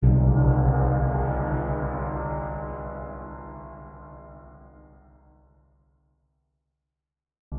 Gong (1).wav